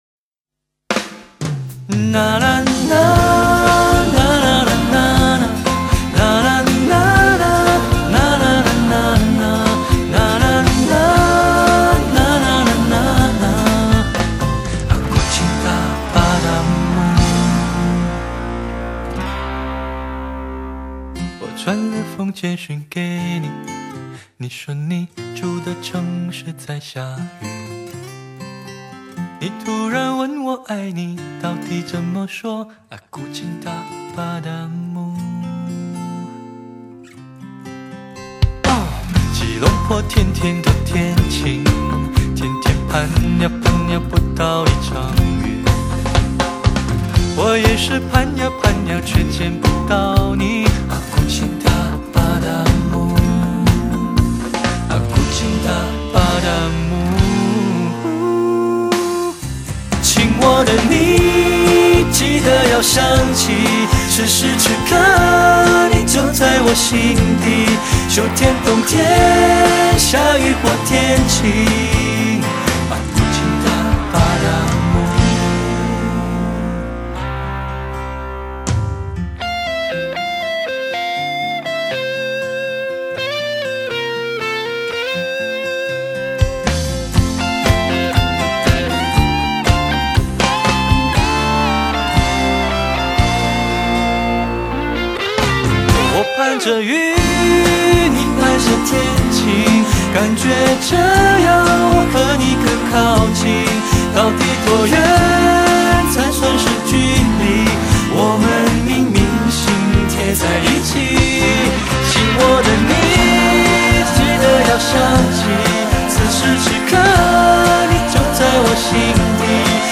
延续了牛式情歌的轻松愉快